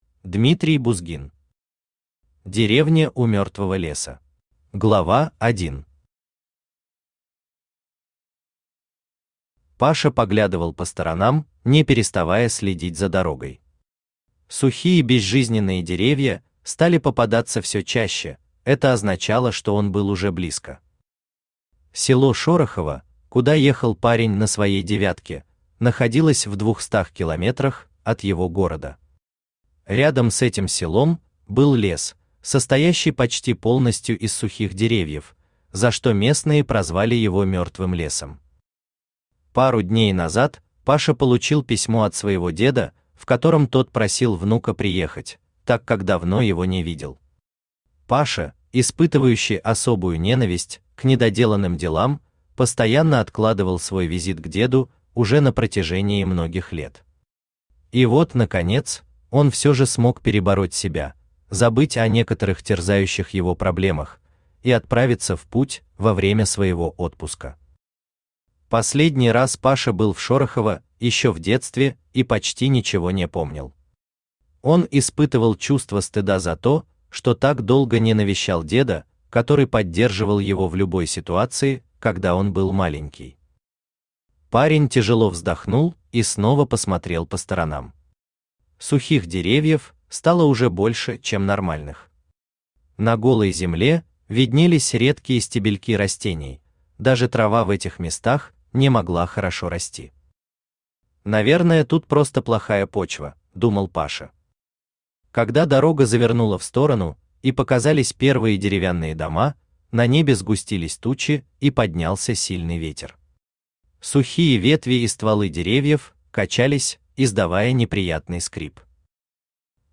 Аудиокнига Деревня у мертвого леса | Библиотека аудиокниг
Aудиокнига Деревня у мертвого леса Автор Дмитрий Бузгин Читает аудиокнигу Авточтец ЛитРес.